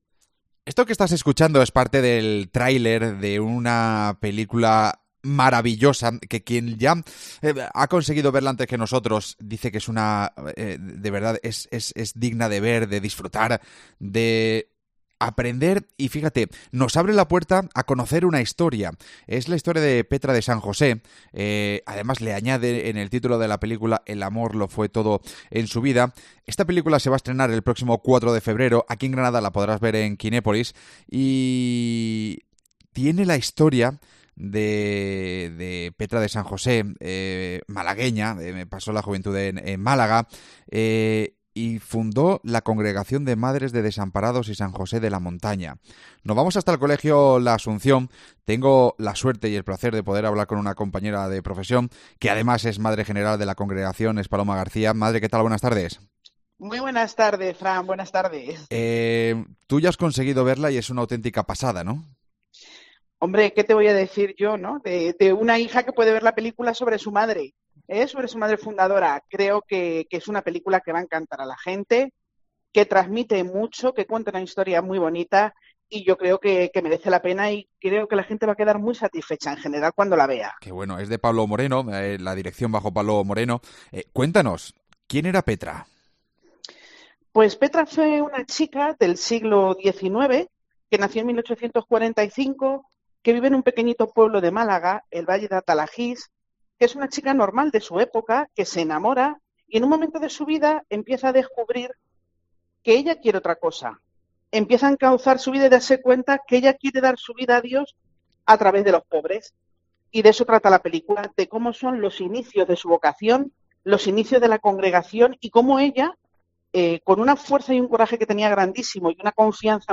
Hablamos con